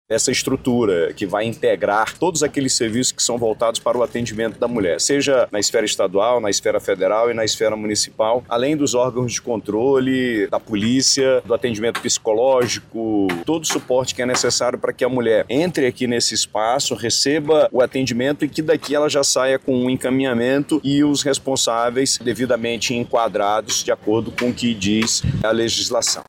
O governador Wilson Lima ressaltou os serviços que vão ser ofertados como forma de proteção e apoio às mulheres vítimas de violência.